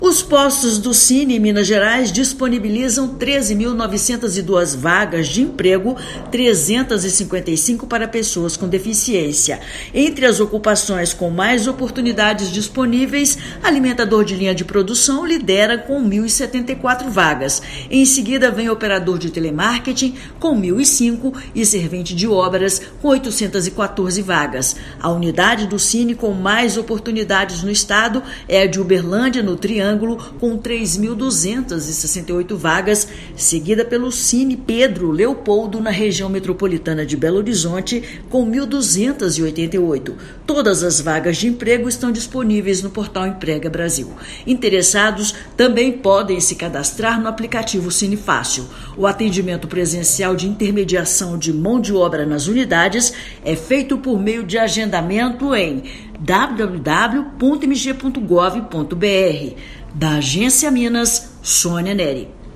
[RÁDIO] Postos do Sine em Minas Gerais disponibilizam 13,9 mil vagas de emprego
Alimentador de linha de produção e operador de telemarketing estão entre as ocupações com mais oportunidades neste início de semana. Ouça matéria de rádio.